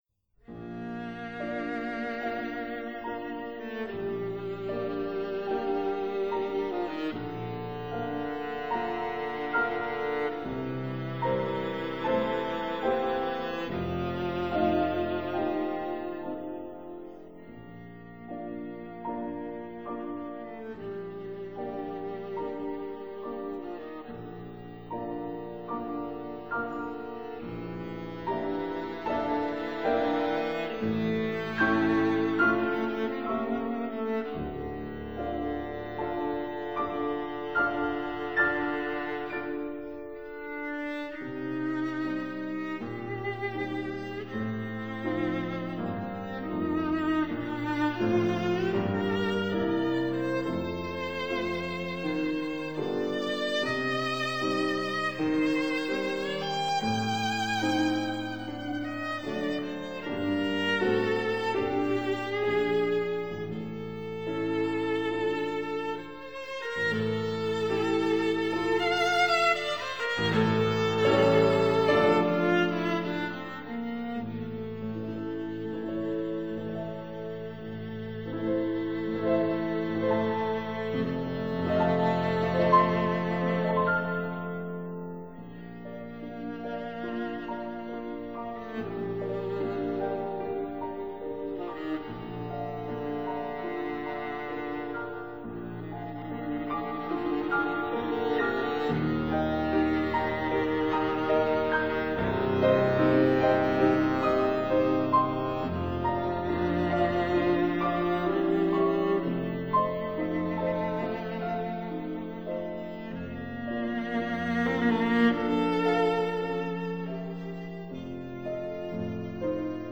Sonata for violin & piano in A major, M. 8- 1.